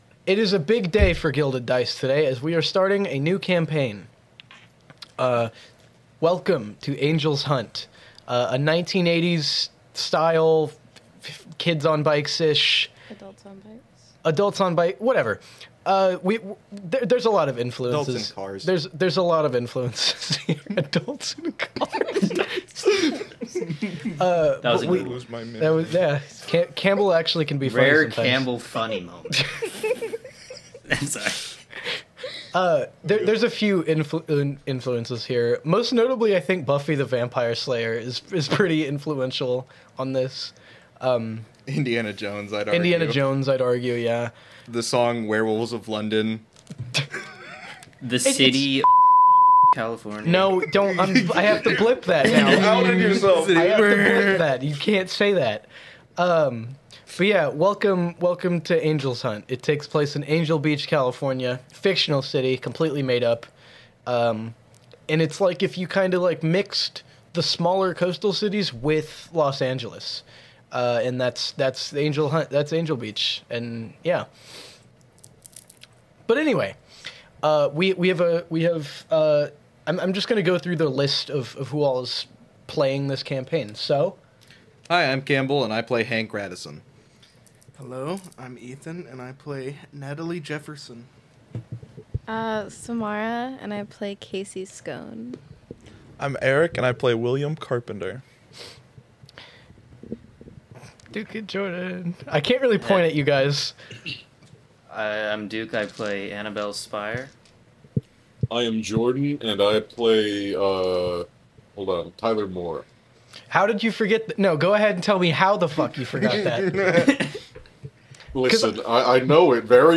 Be a guest on this podcast Language: en Genres: Comedy , Games , Improv , Leisure Contact email: Get it Feed URL: Get it iTunes ID: Get it Get all podcast data Listen Now... Gilded Dice: Angel's Hunt - S1E1 - Rock Lobster